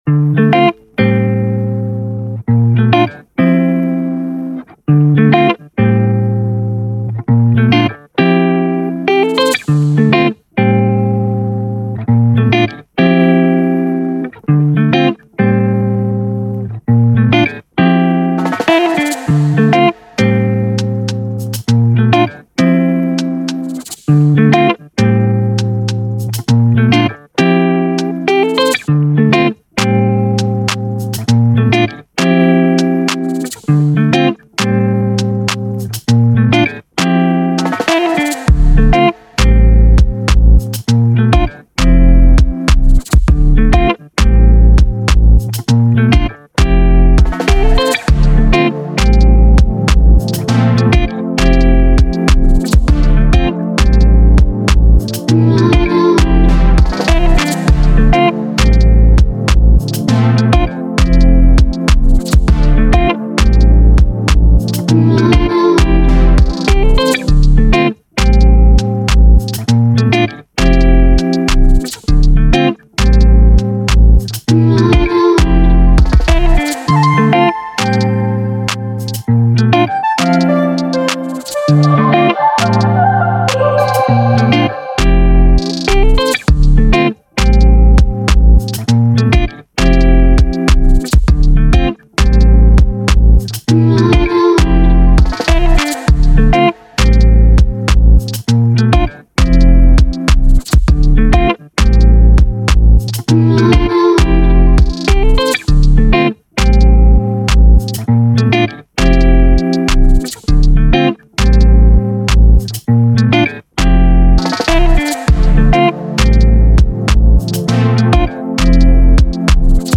D Minor